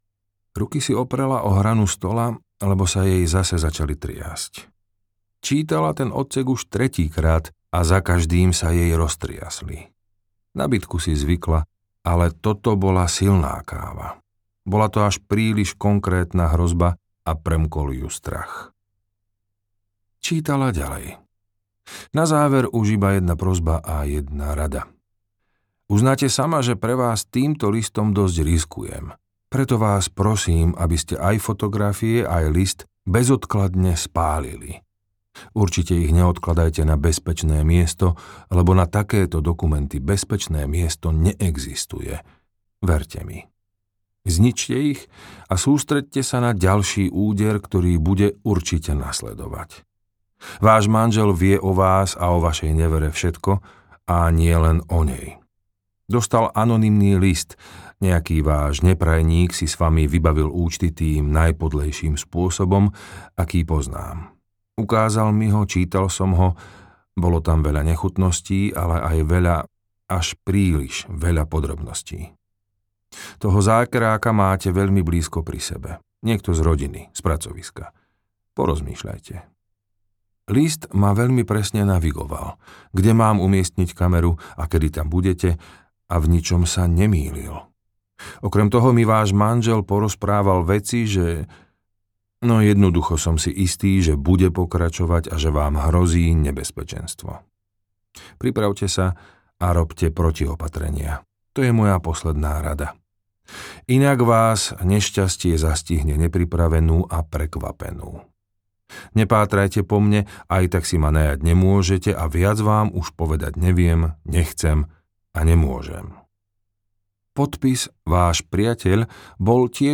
Na podpätkoch audiokniha
Ukázka z knihy
na-podpatkoch-audiokniha